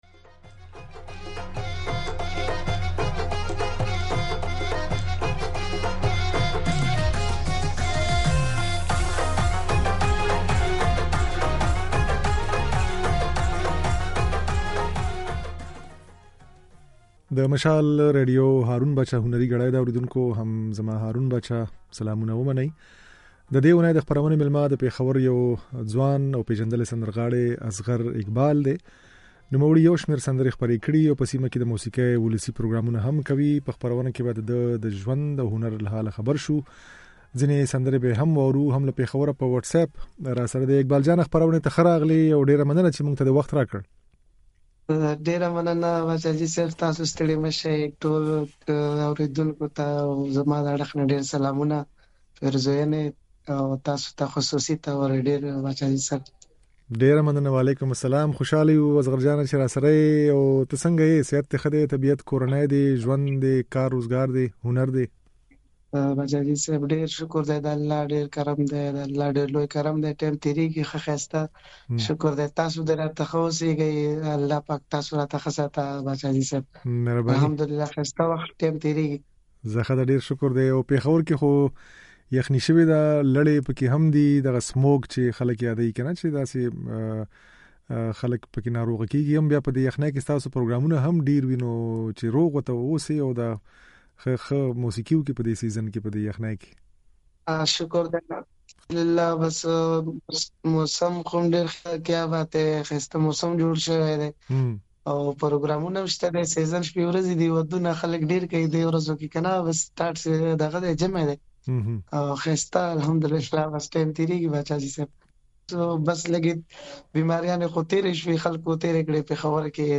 د نوموړي دا خبرې او ځينې سندرې يې په خپرونه کې اورېدای شئ.